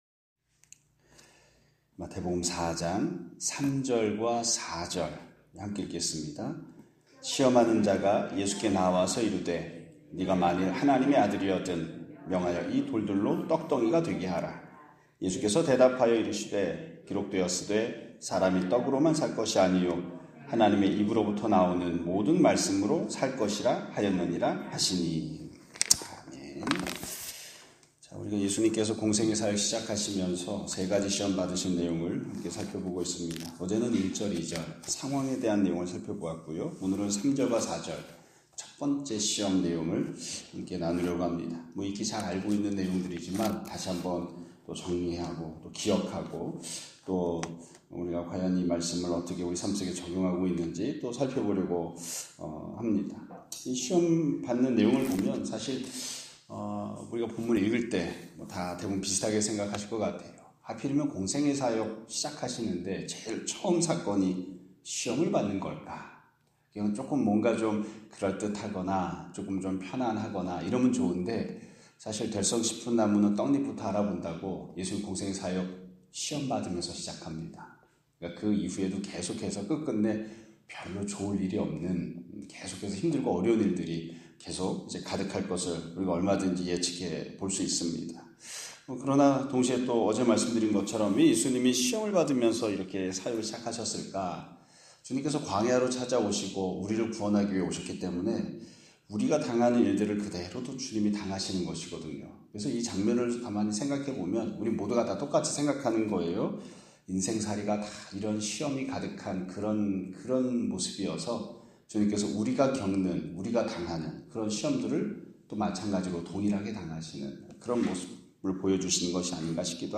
2025년 4월 24일(목요일) <아침예배> 설교입니다.